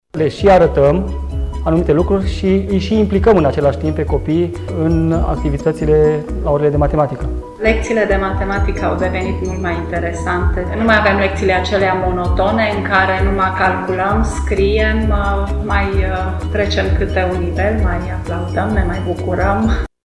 Profesorii de matiematică